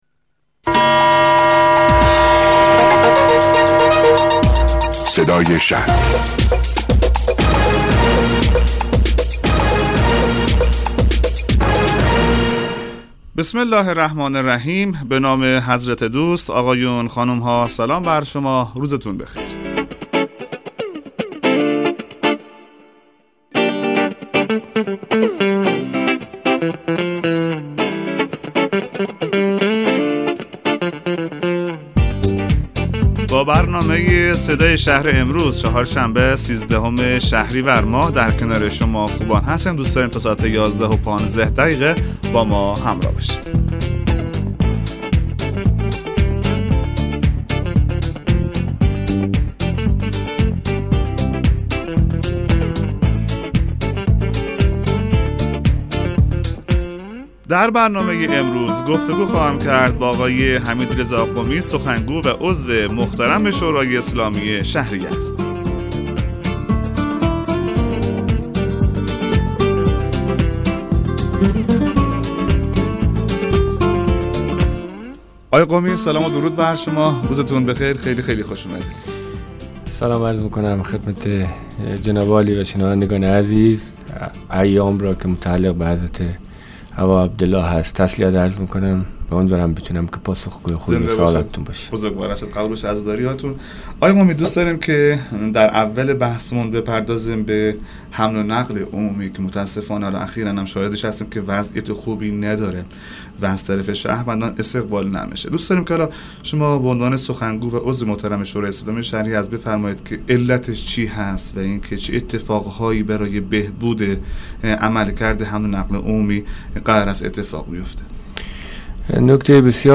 حمید رضا قمی؛ عضو شورای اسلامی شهر یزد و سخنگوی شورا، درباره مشکلات حمل و نقل عمومی شهر یزد و راهکارهای رفع این مشکلات توضیحاتی ارائه می‌دهد.